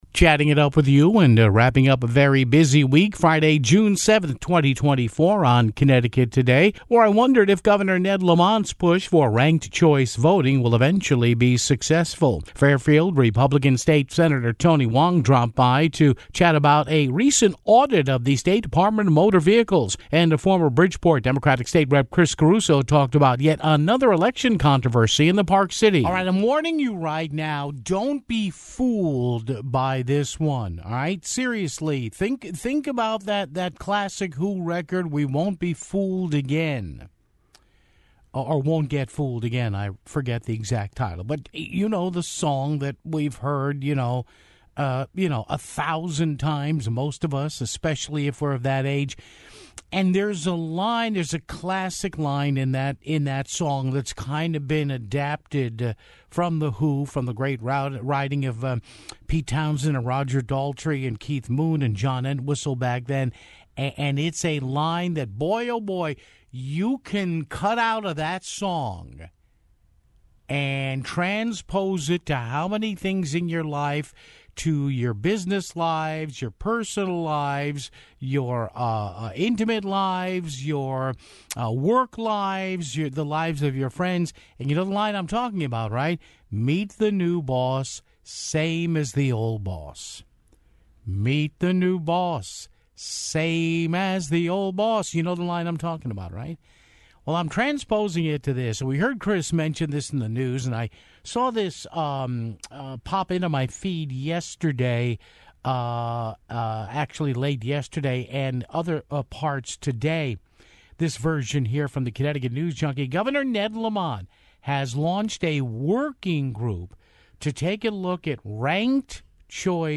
Fairfield GOP State Sen. Tony Hwang dropped by to chat about a recent audit of the State Department of Motor Veh...
Former Bridgeport State Rep. Chris Caruso talked about another election controversy in the Park City (26:29)